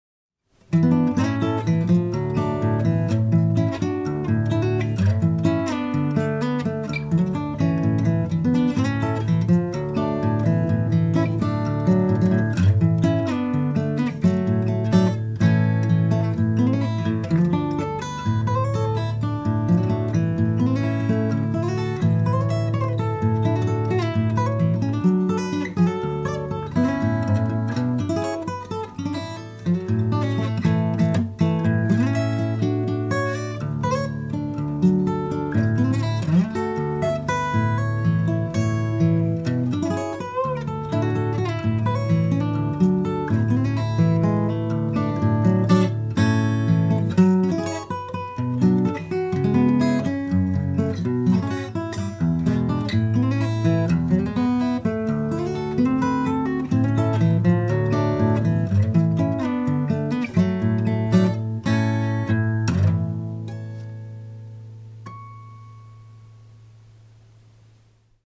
Waghorn Hydra acoustic (Indian Rosewood back and sides)